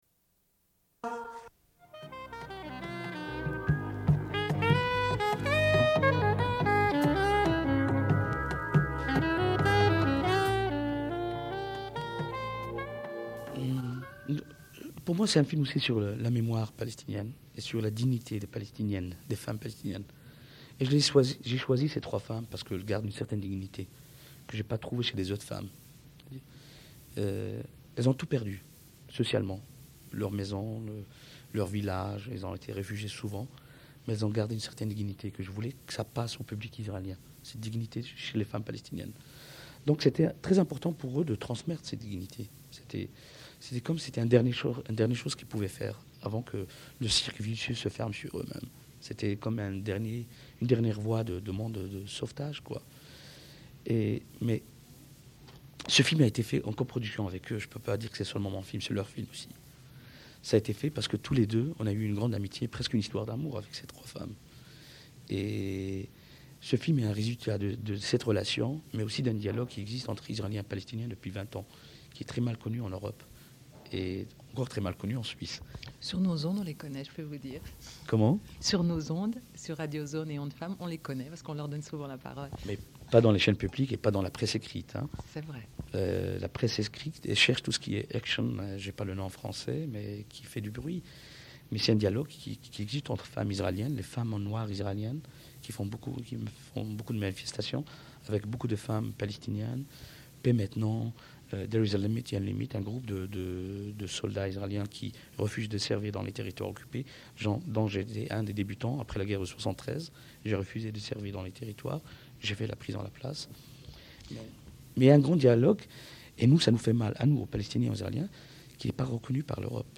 Une cassette audio, face A29:32
Sommaire de l'émission : « spécial Fribourg », diffusion d'entretiens sur le Festival de films de Fribourg.